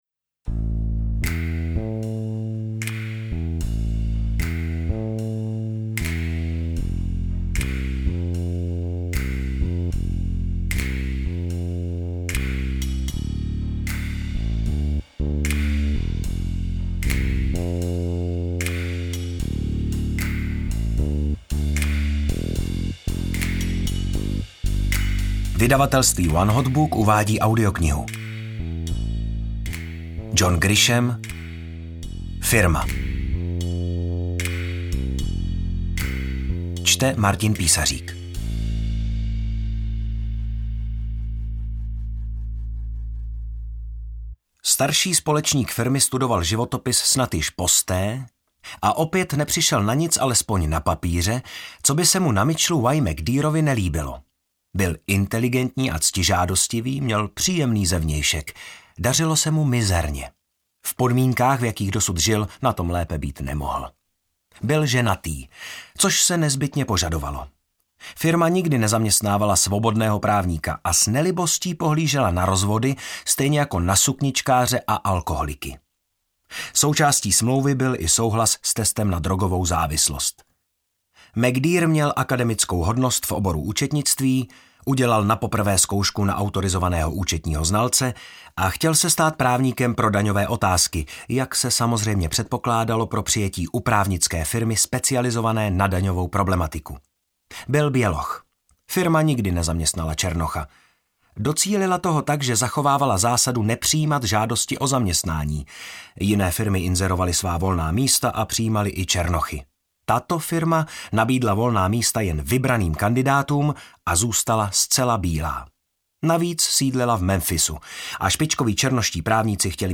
AudioKniha ke stažení, 51 x mp3, délka 15 hod. 14 min., velikost 1243,0 MB, česky